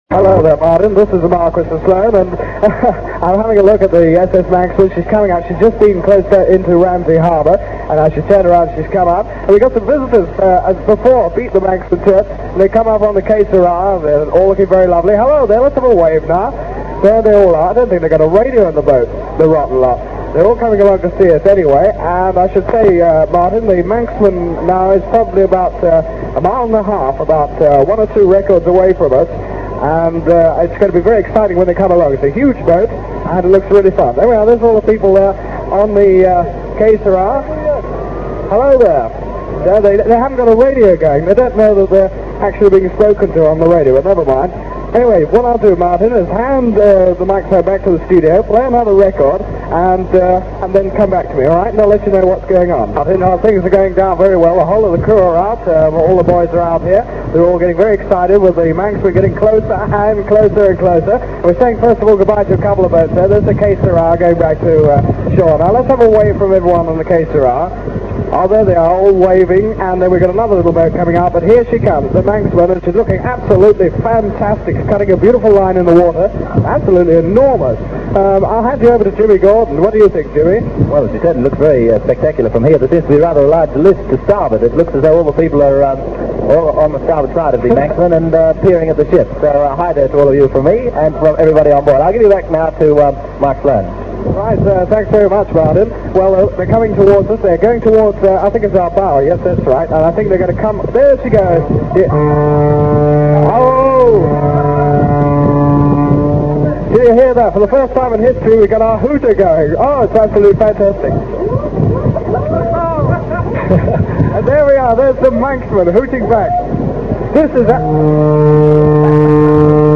click to hear audio On the 31st August 1967, just hours before the new law took effect, some 2000 people took their last opportunity to visit Radio Caroline North on board the Isle of Man ferry Manxman.